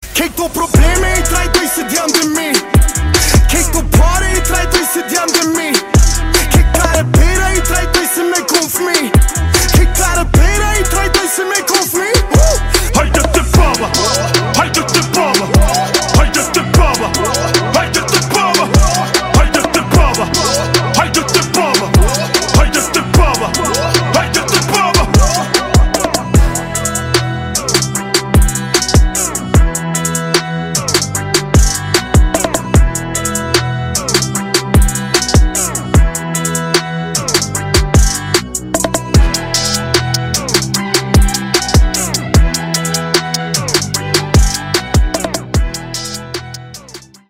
Categoria Rap/Hip Hop